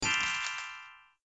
sparkly.ogg